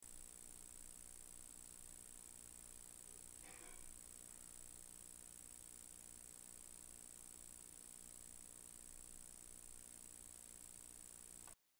When I record there is a weird buzzing sound
Hey, I have an AT2020 mic (audio-technica) with a Neewer 1-Channel 48V Phantom Power Supply and it makes a weird buzzing sound.